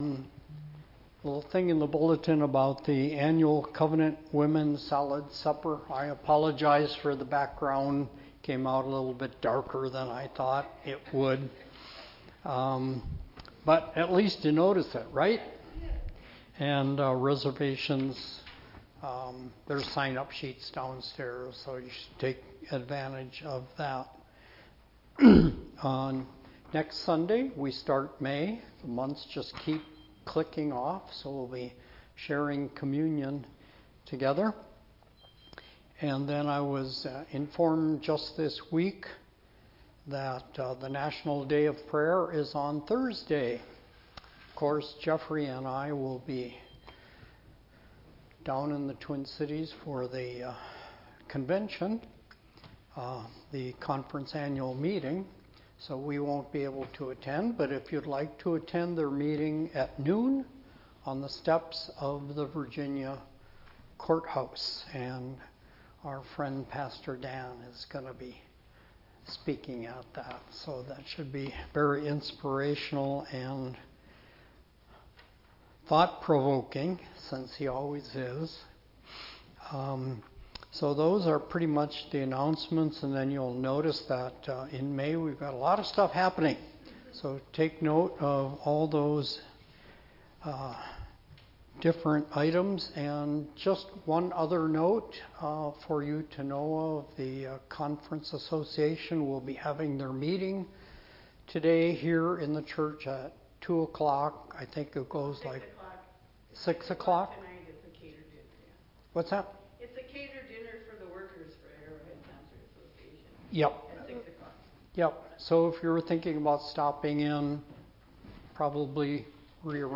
Sermon 4.27.25